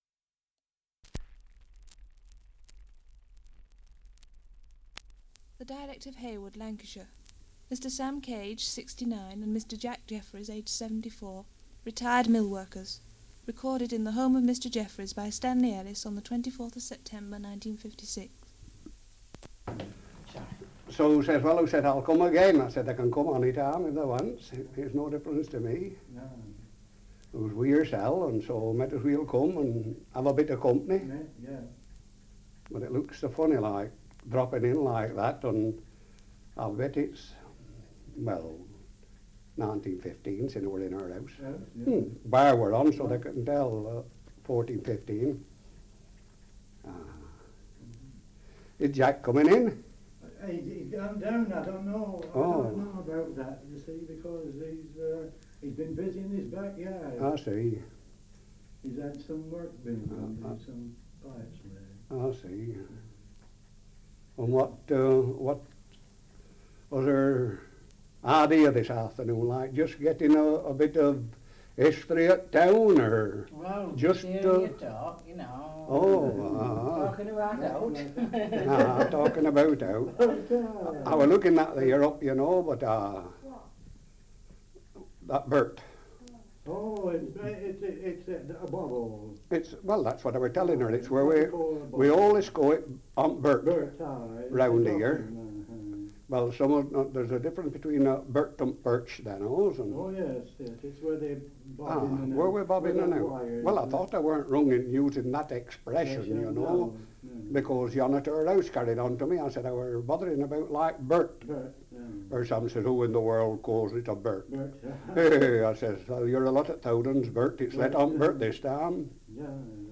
Dialect recording in Heywood, Lancashire
78 r.p.m., cellulose nitrate on aluminium